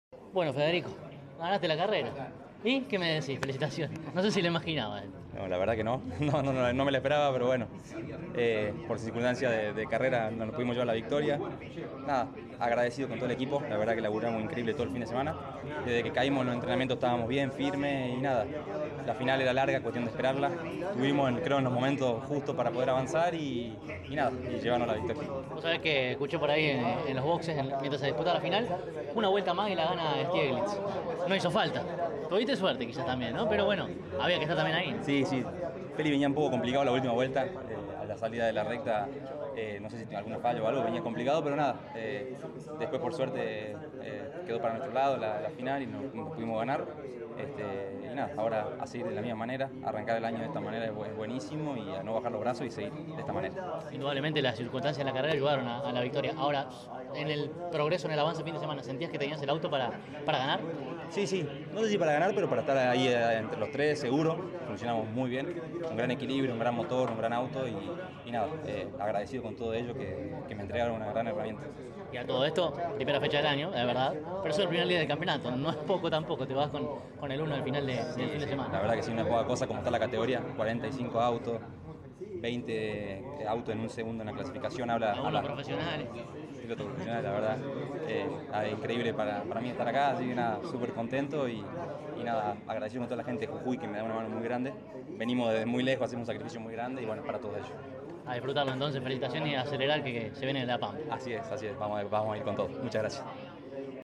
CÓRDOBA COMPETICIÓN estuvo presente en el trazado platense y dialogó con los protagonistas más importantes al cabo de cada la final de la clase mayor.